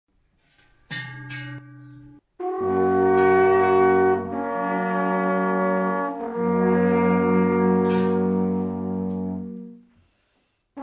alphorn.mp3